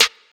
SouthSide Snare Roll Pattern (23).wav